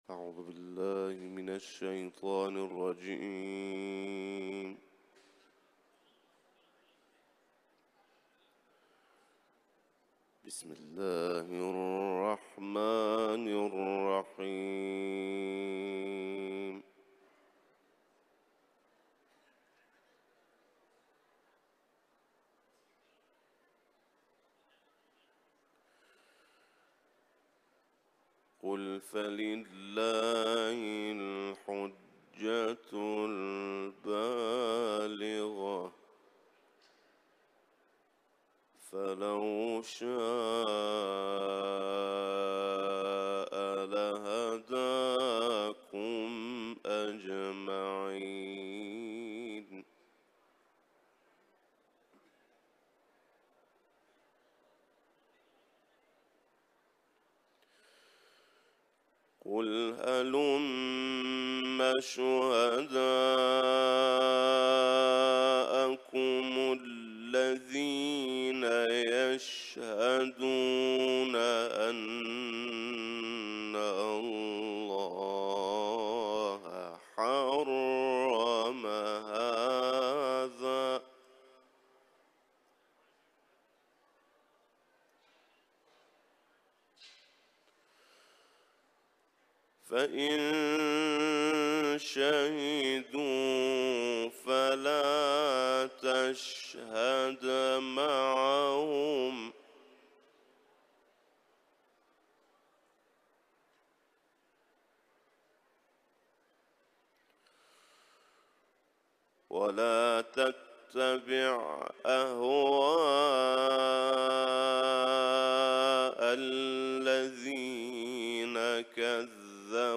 Etiketler: İranlı kâri ، Kuran tilaveti ، Kuran Kerim